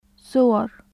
/s/を発音するときのように舌先を歯茎に近づけたまま，奥舌を口蓋垂に近づけて発音される/s̩/ [sʶ] の音です。
/ṣ/ 無声・歯茎・摩擦・口蓋垂化音/s̩/ ص /s̩aːd/ s̩ （アルファベット） قصة /qis̩s̩a/ 物語 قصص /qis̩as̩/ 物語 （複数形） قصير /qas̩iːr/ 短い عصير /ʕas̩iːr/ ジュース صورة /s̩uːra/ 写真 صور /s̩uwar/ 写真 （複数形） مصر /mis̩r/ エジプト رخيص /raχiːs̩/ 安い